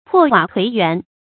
破瓦颓垣 pò wǎ tuí yuán 成语解释 破屋断墙。
成语注音 ㄆㄛˋ ㄨㄚˇ ㄊㄨㄟˊ ㄧㄨㄢˊ